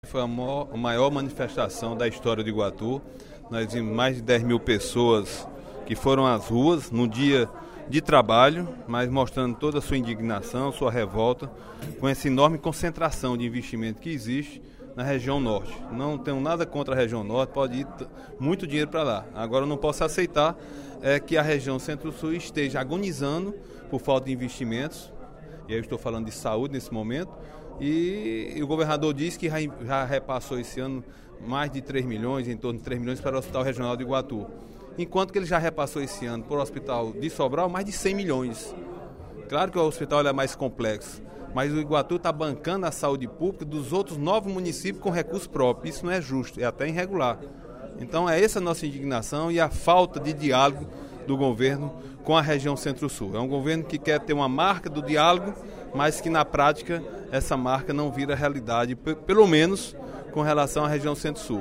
O deputado Agenor Neto (PMDB) fez pronunciamento, nesta terça-feira (29/09), durante o primeiro expediente da Assembleia Legislativa, para informar que Iguatu “parou” na última sexta-feira, durante manifestação em defesa de melhorias para a região centro-sul.